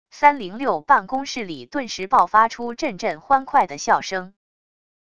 三零六办公室里顿时爆发出阵阵欢快的笑声wav音频生成系统WAV Audio Player